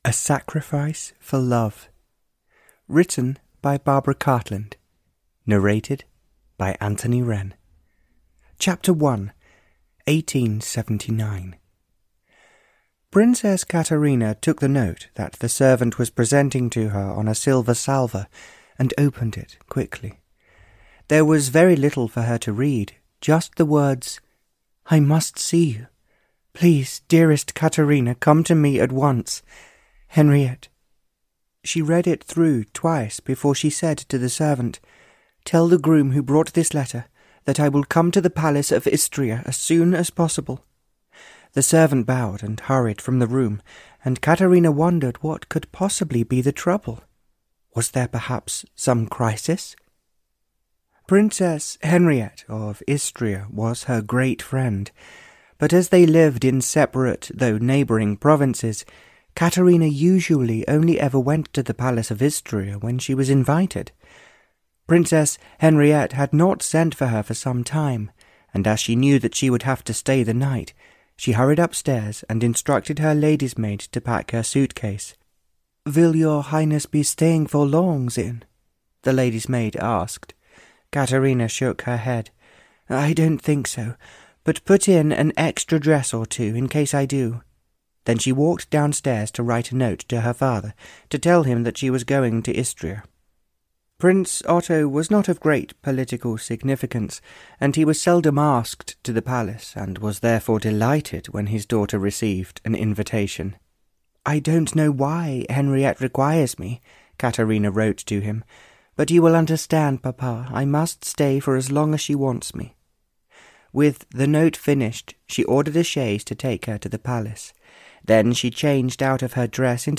A Sacrifice for Love (EN) audiokniha
Ukázka z knihy